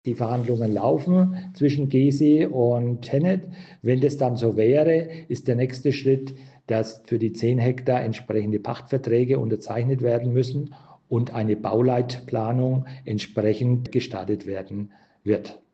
Wir sprechen mit Ulrich Werner, dem Bergrheinfelder Bürgermeister.